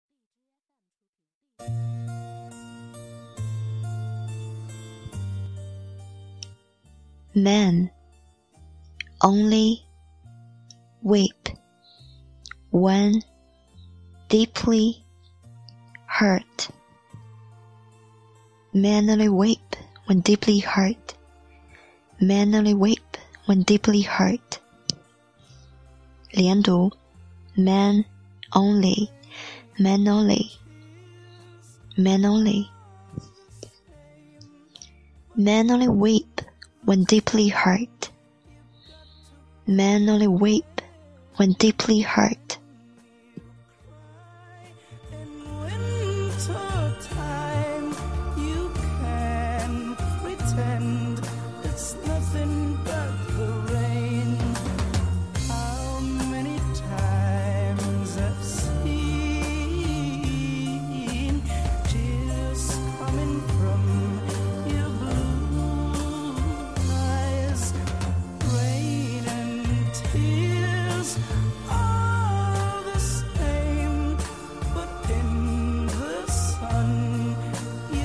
1.连读men only
2.吐字清楚，发音饱满